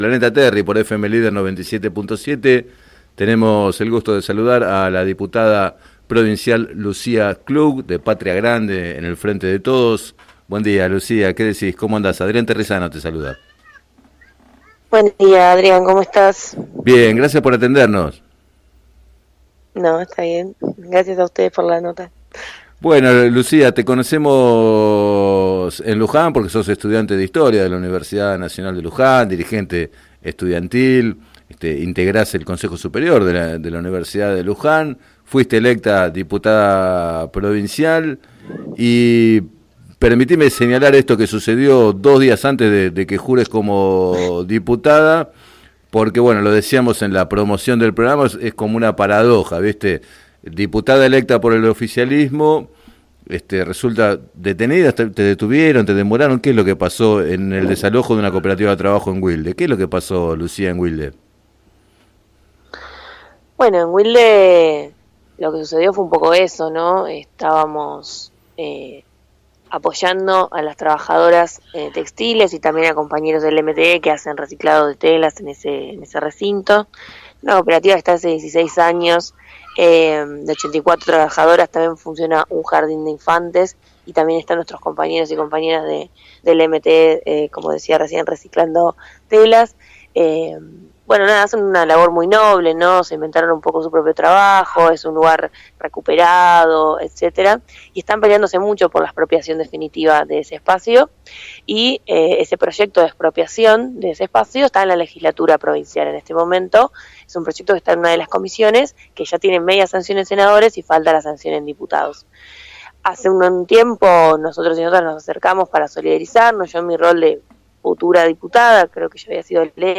En declaraciones al programa “Planeta Terri” de FM Líder 97.7, la legisladora señaló la importancia de la militancia juvenil con voluntad transformadora para poder avanzar en procesos con mayores niveles de cambios para la sociedad.